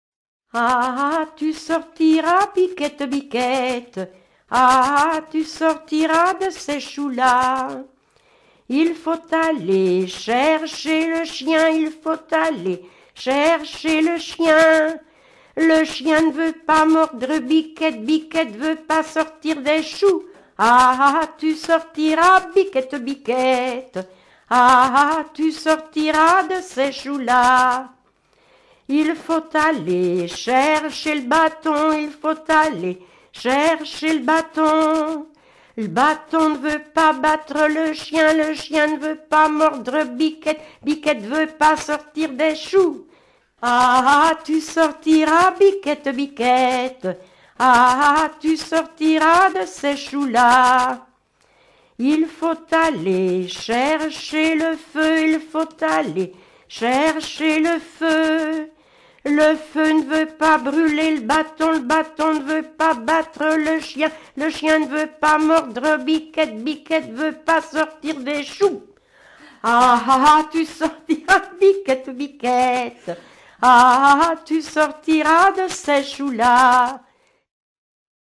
rondes enfantines (autres)
Genre énumérative
Pièce musicale éditée